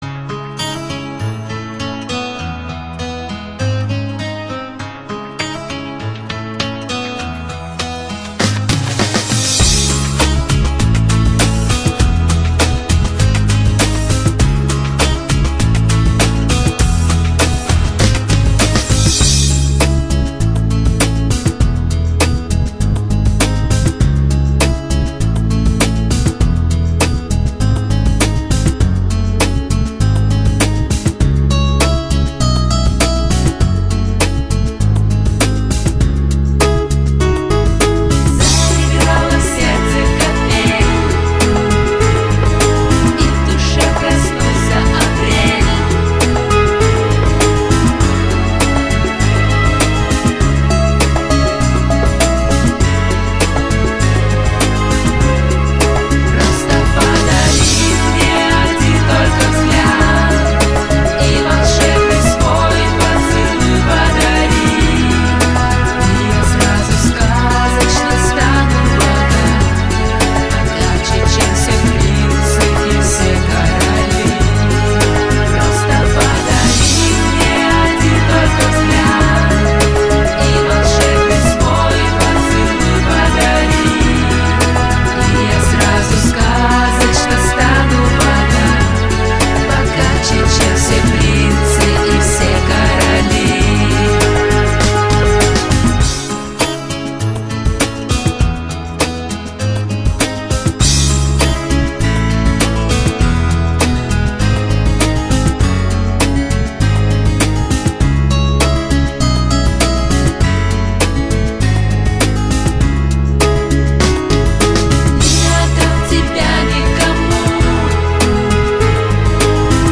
Часто используемые минусовки хорошего качества